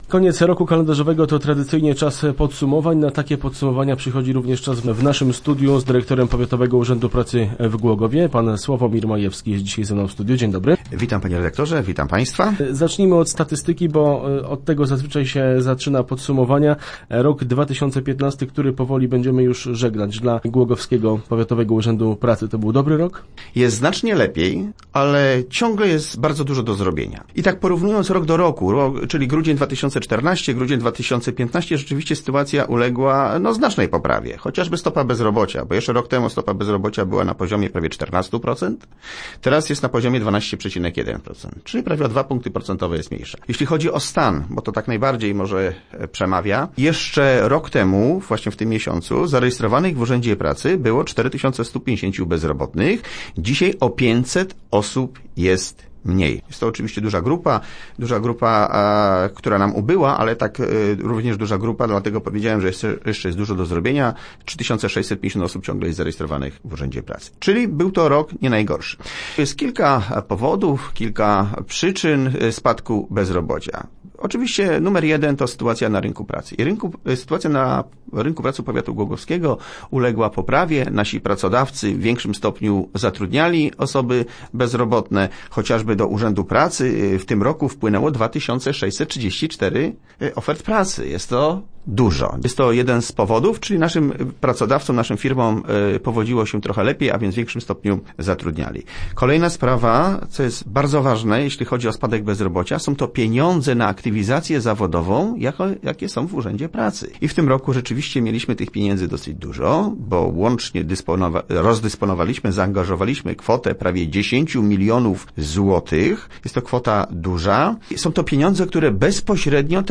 Start arrow Rozmowy Elki arrow Jest znacznie lepiej, ale ciągle dużo do zrobienia